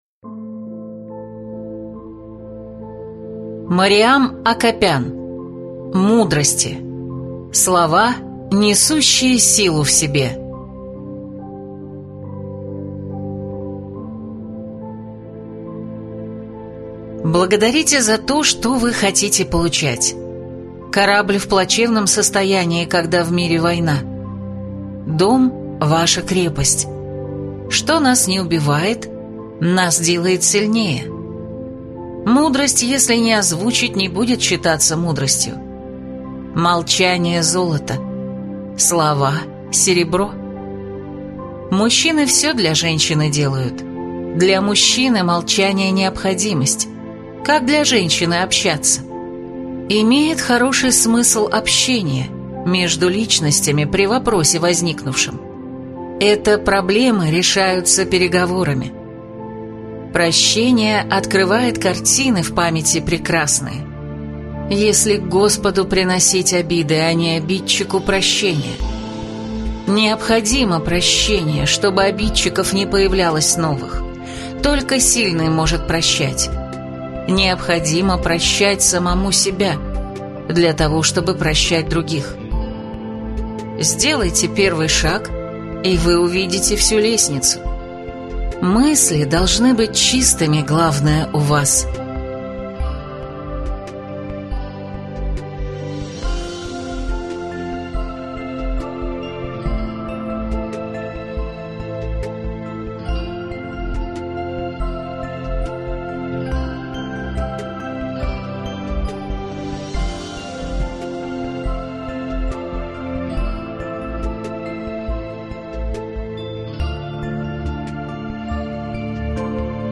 Аудиокнига Мудрости | Библиотека аудиокниг